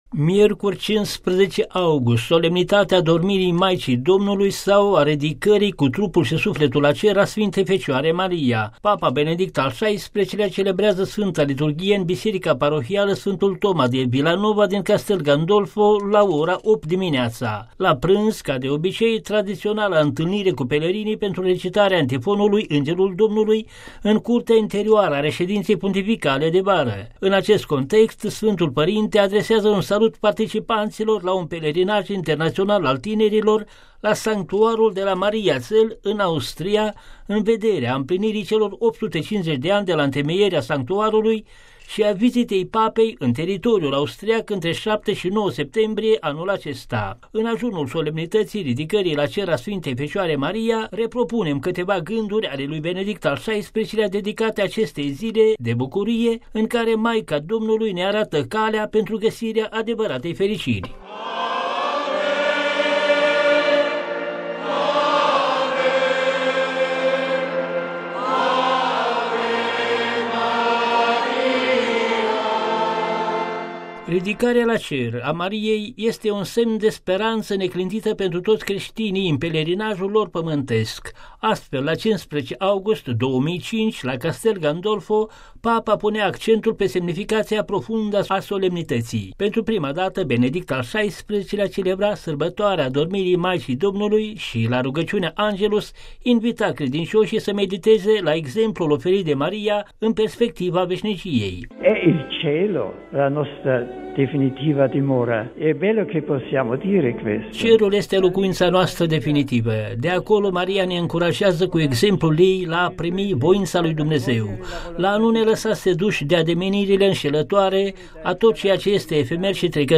Ins - secvenţe muzicale „Ave Maria”.